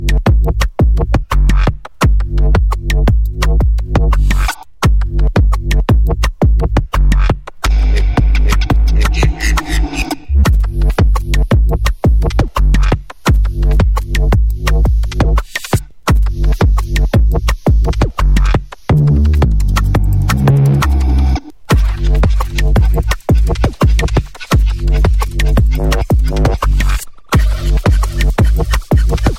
TOP >Vinyl >Drum & Bass / Jungle
TOP > HARD / TECH